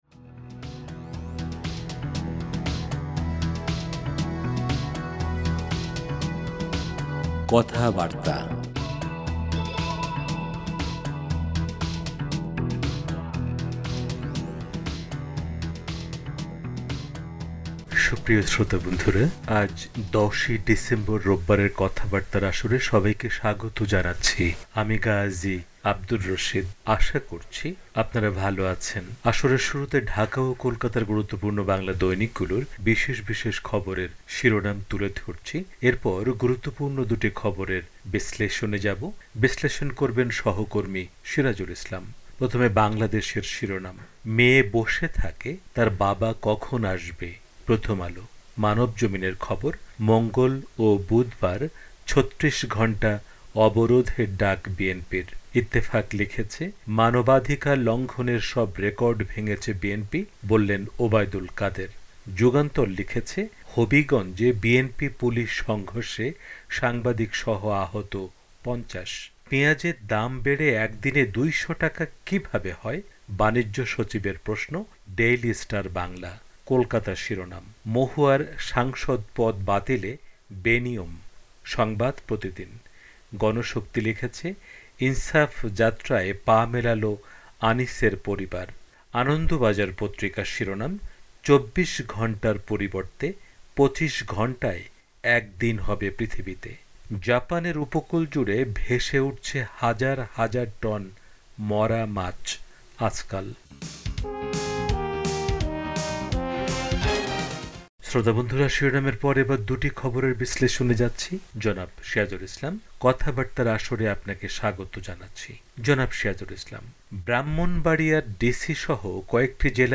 পত্রপত্রিকার পাতা